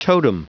Prononciation du mot totem en anglais (fichier audio)
Prononciation du mot : totem